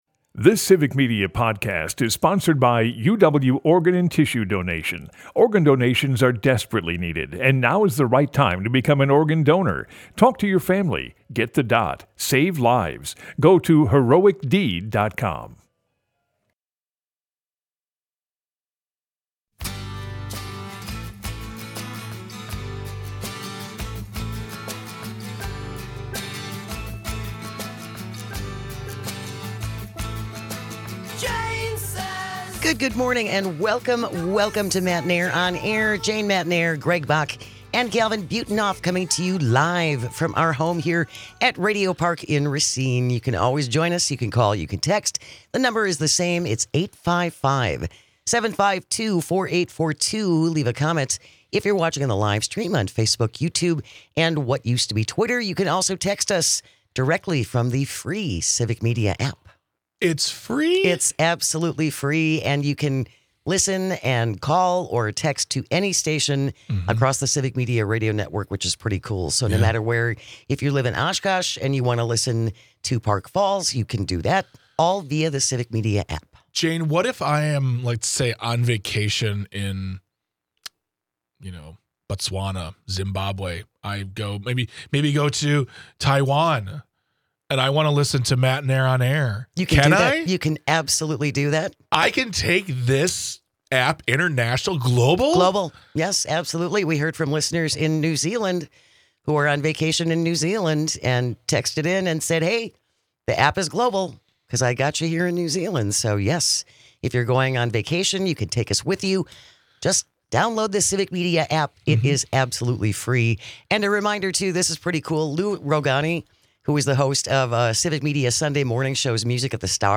They cover all the news that affects you with humor and a unique perspective.